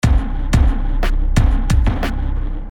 描述：重型鼓由钢弹簧的声音支持，加上肮脏的小鼓和非常安静的低音。
Tag: 90 bpm Hip Hop Loops Drum Loops 459.42 KB wav Key : Unknown